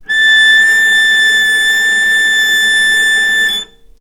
vc_sp-A6-ff.AIF